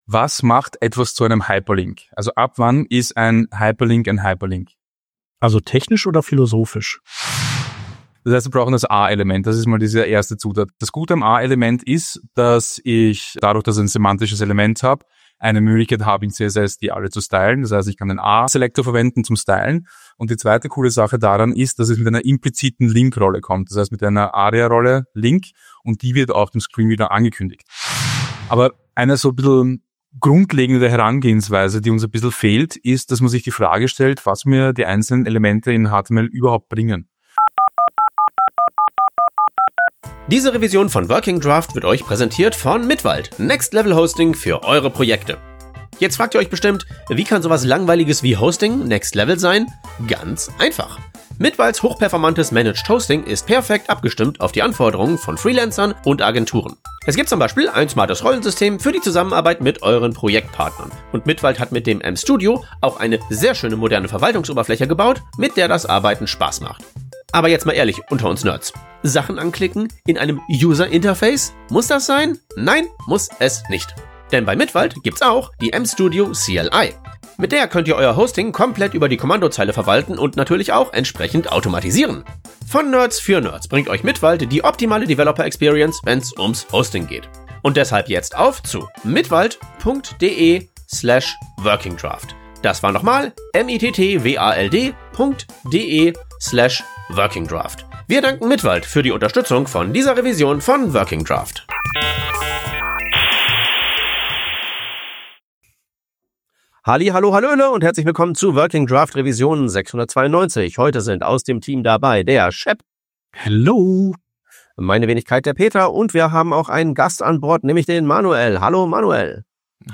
Working Draft ist ein wöchentlicher News-Podcast für Webdesigner und Webentwickler